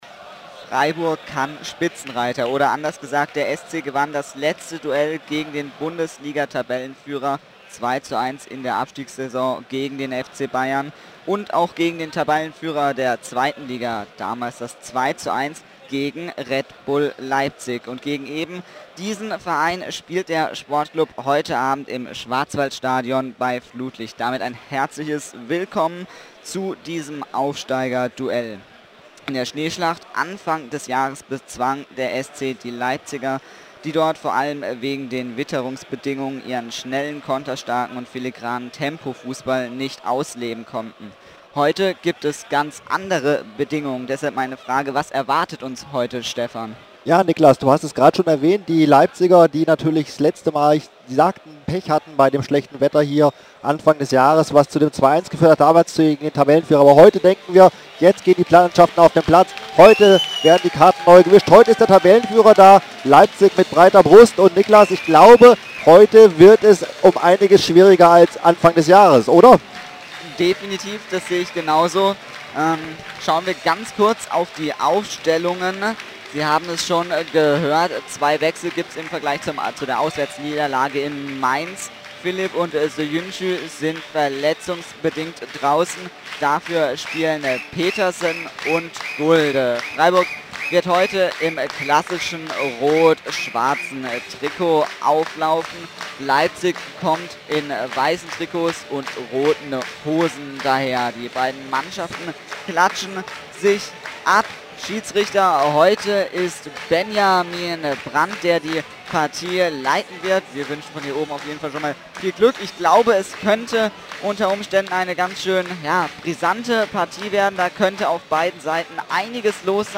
Die Spiel-Reportage im Player
Ort Schwarzwald-Stadion, Freiburg